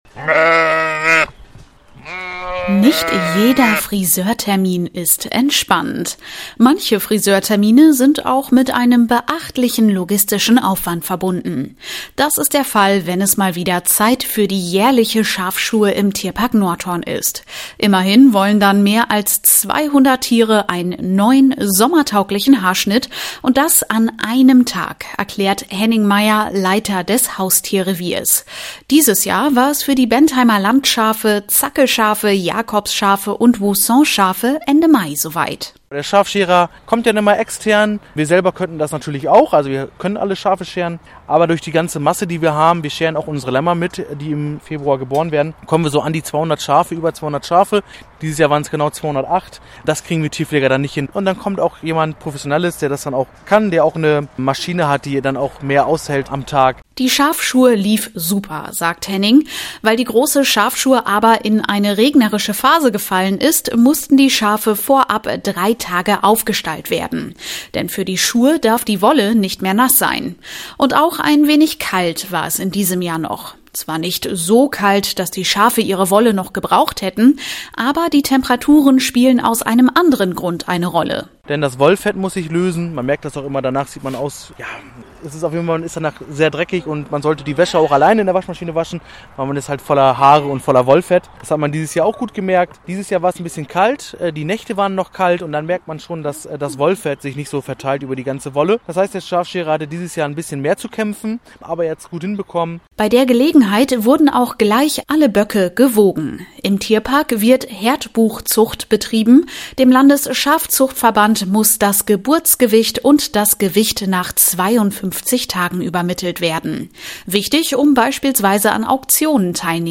Die Besucherinnen und Besucher konnten bei der großen Schafschur zuschauen.